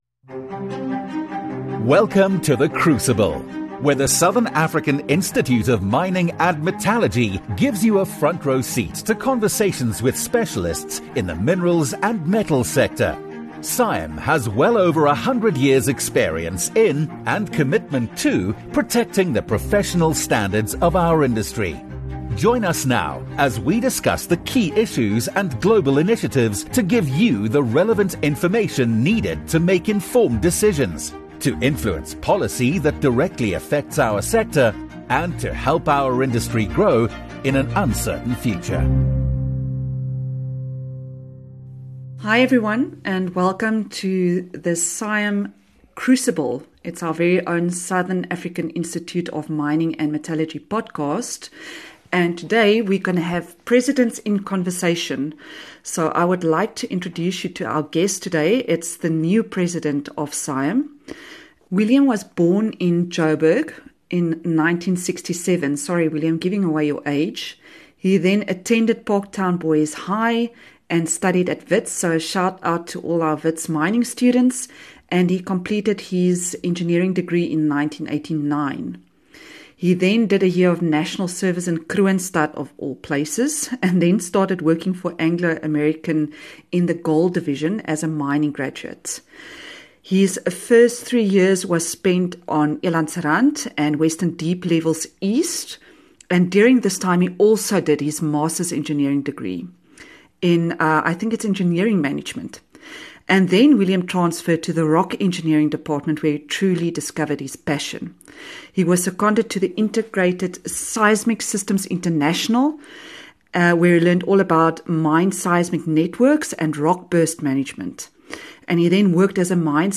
Presidents in Conversation